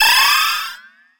special_item_popup_01.wav